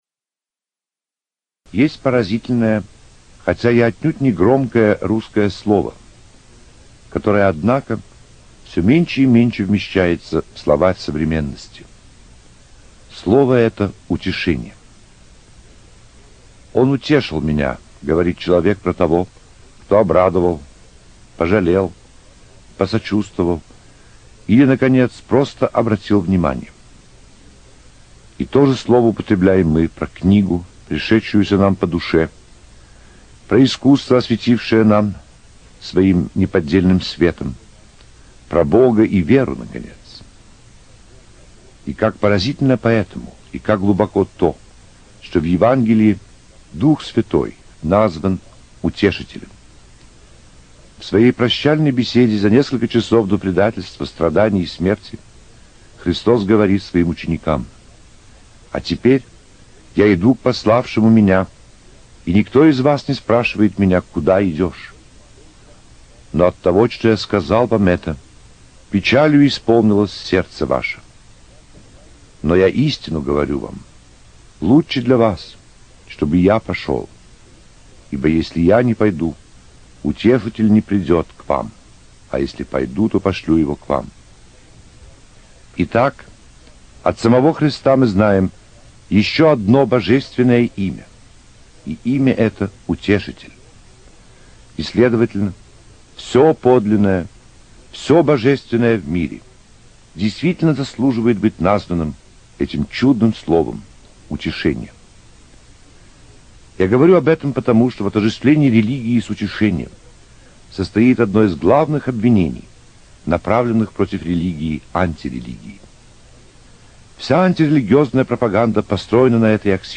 Проповеди на Вознесение Господне